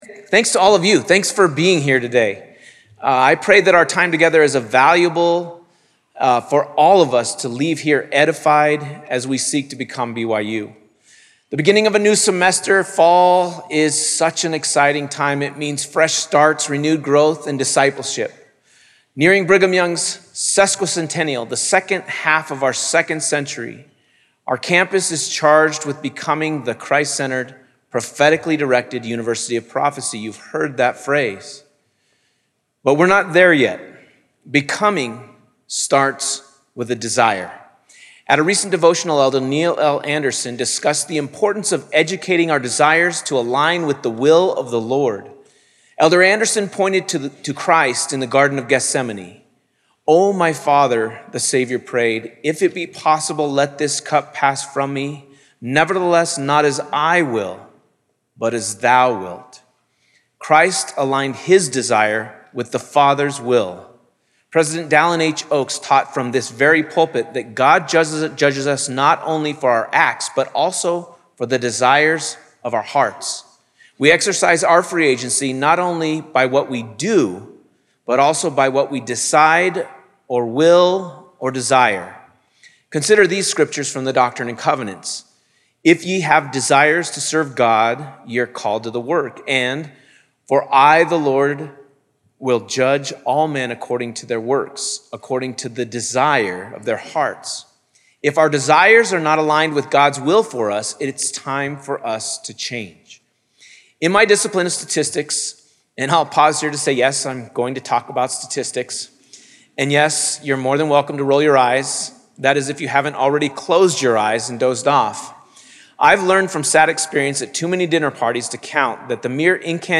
President of Brigham Young University